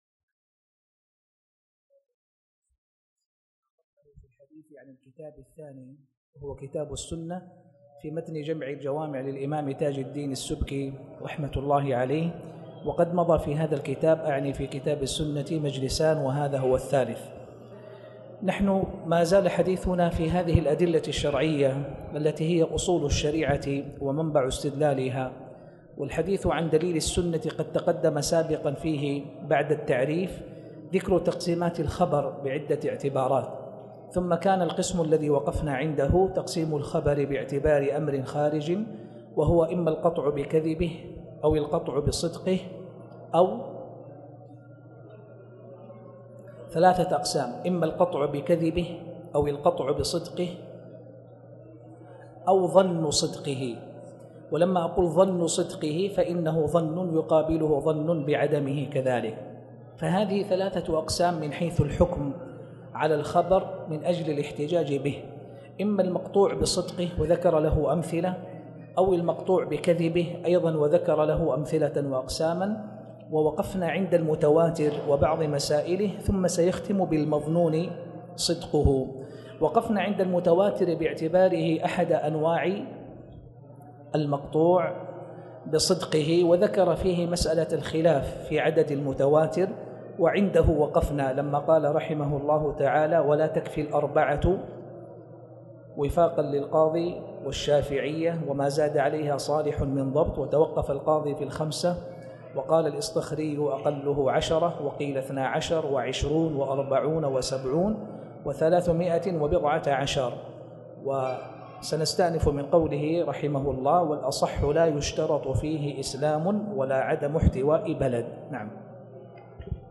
تاريخ النشر ٢ صفر ١٤٣٨ هـ المكان: المسجد الحرام الشيخ